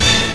hitTTE68007stabhit-A.wav